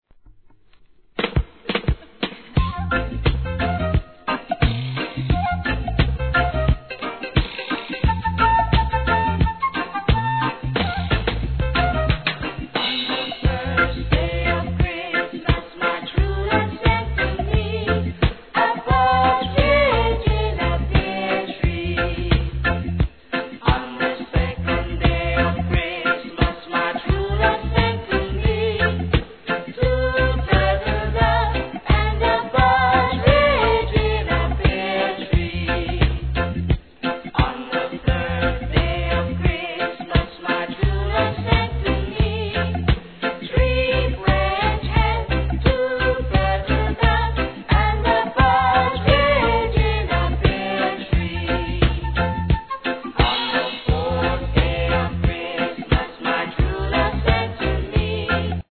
メチャクチャ楽しいレゲエ・クリスマス♪